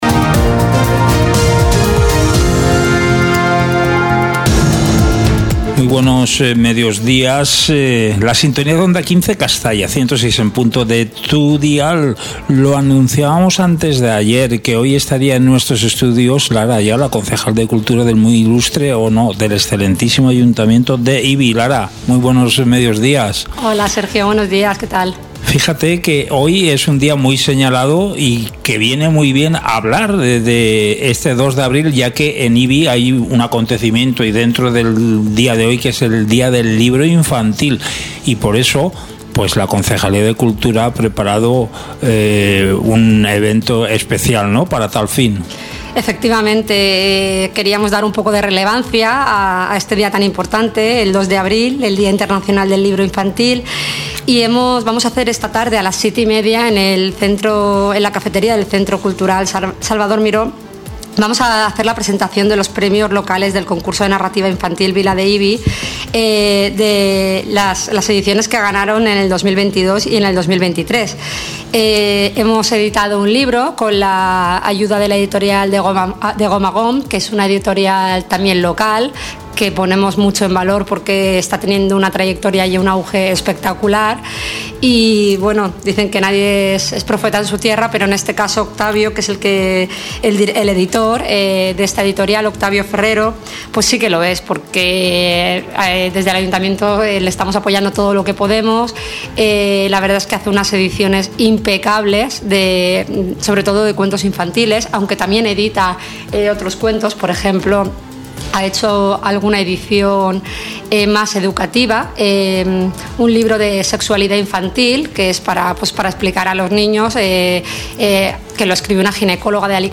Hoy en nuestro Informativo, contamos con la presencia de Lara Ayala, concejala de Cultura, Bienestar Animal, Participación Ciudadana y Comunicación del Excmo. Ayuntamiento de Ibi.
En esta entrevista, repasamos la variada programación prevista para este mes de Abril en dos de los principales espacios culturales de la localidad: el Teatro Río y el Centre Cultural Salvador Miró.